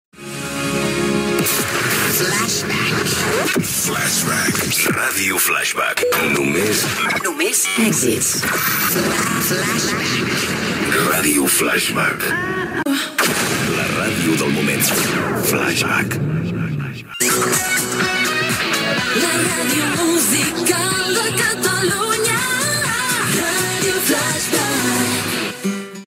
Indicatiu de l'emissora.